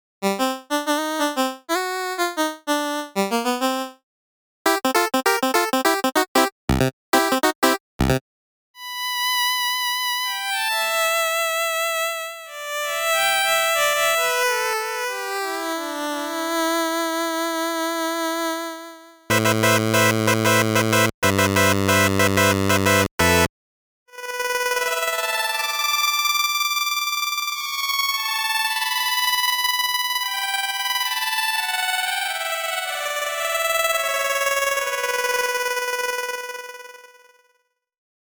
こちらはみなサイン波でできた音色たちで、違うのはエンヴェロープのみです。
同じ波形から異なる音色を作る
p-timbre-chiptunes.mp3